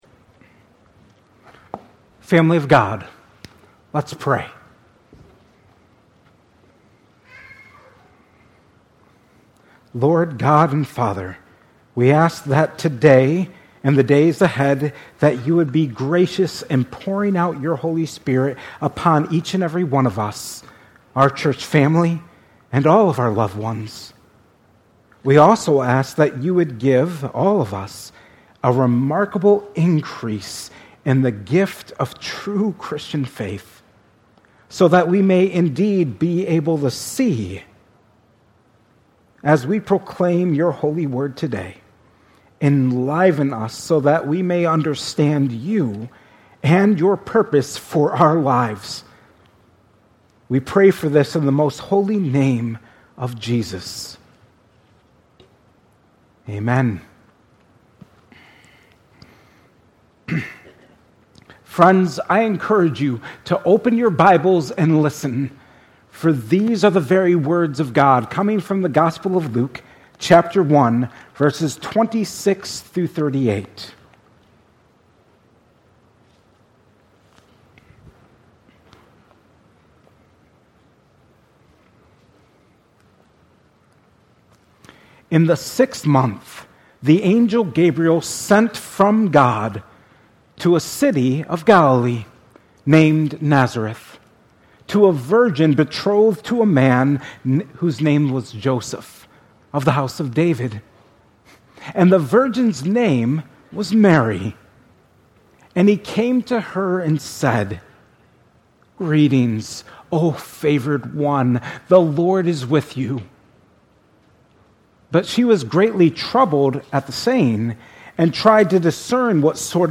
2024 at Cornerstone Church.